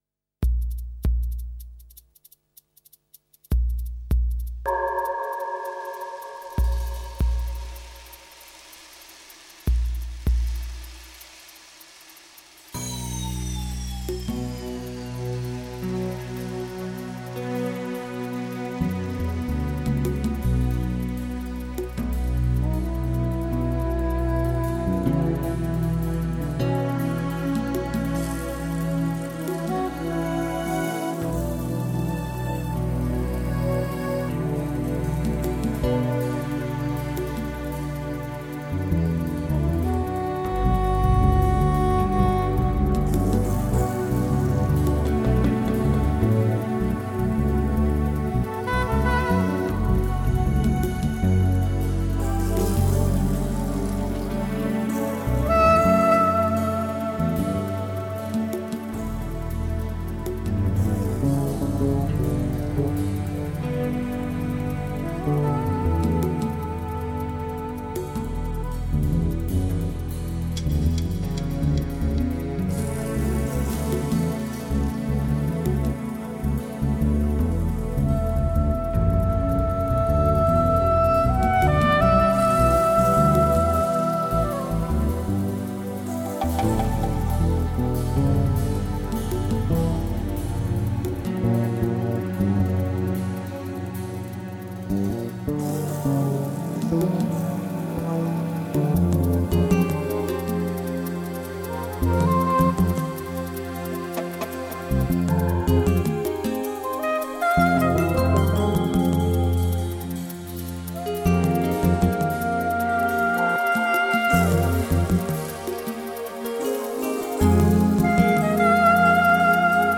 minimalistic lyrical content
sensuous melodies and hypnotic rhythm patterns